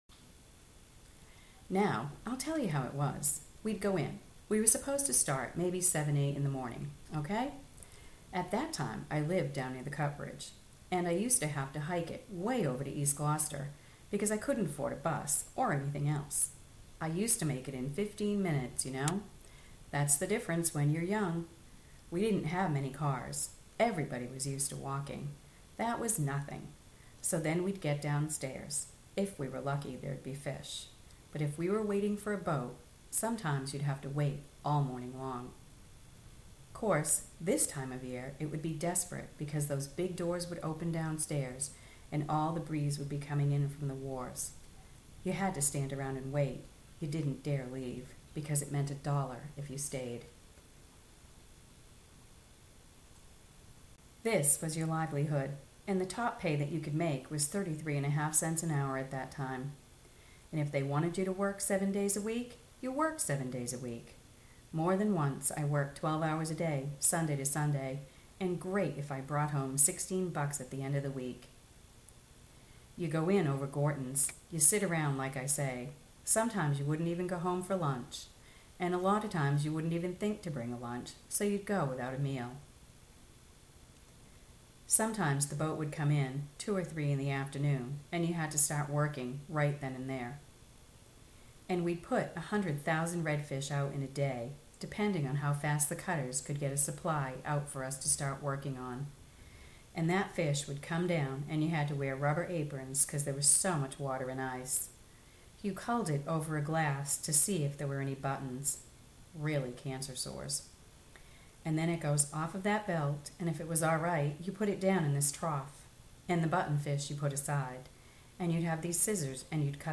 Immerse yourself in the rhythm of the work women undertook throughout the twilight hours. Learn about the intricate details of night labor — from the vibrant hum of a bustling environment to the camaraderie formed during the “dirty hour.”